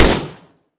phantom_fire1.wav